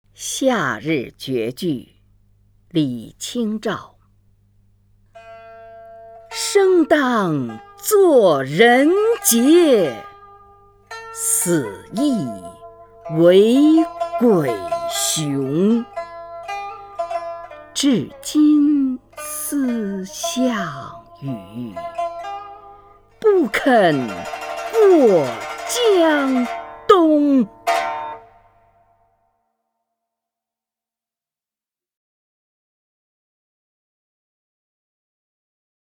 虹云朗诵：《夏日绝句/乌江》(（南宋）李清照) （南宋）李清照 名家朗诵欣赏虹云 语文PLUS